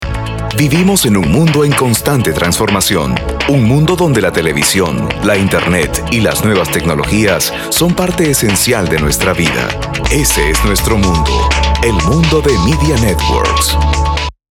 Professional Spanish Latin American Neutral • Conversational • Friendly • Natural • Commercials • Documentaries • Corporate Narrations.
Sprechprobe: Sonstiges (Muttersprache):
Full time Professional Voice Over Artist. As a Native Spanish Male Voice Talent, has a wide range of vocal skills